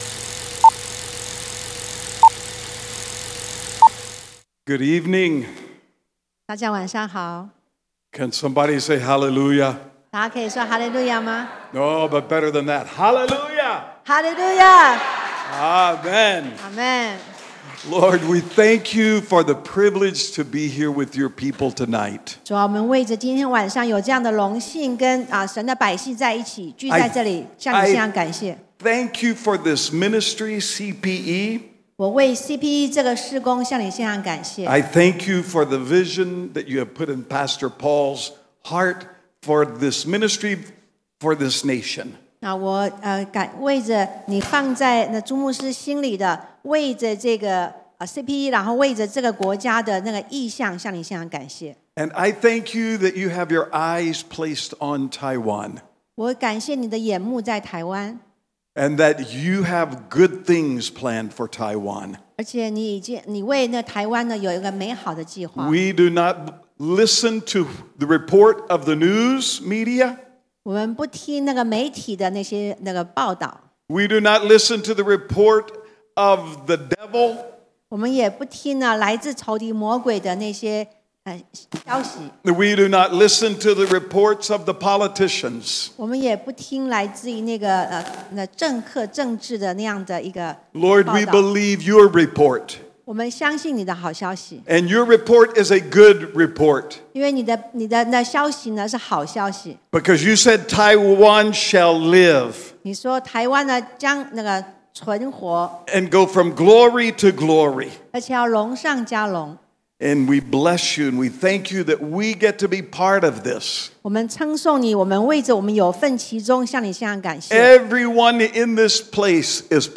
地點：台南CPE領袖學院